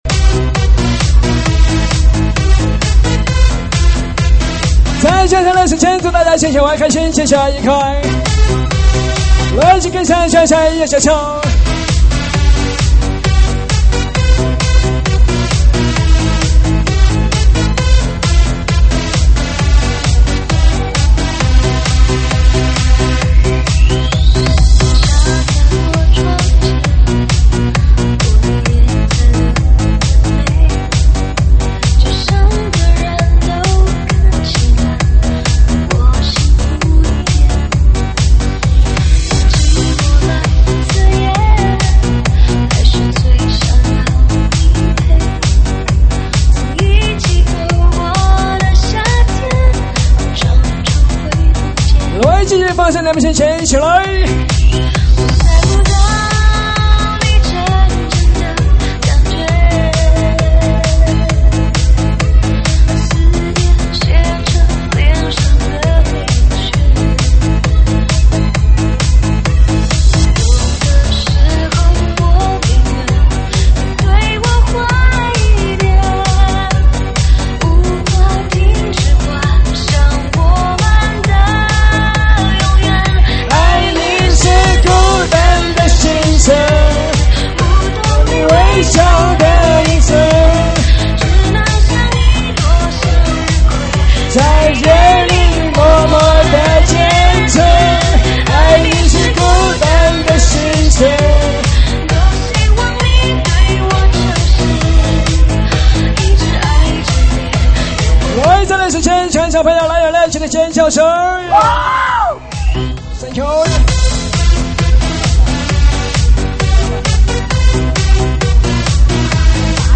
喊麦现场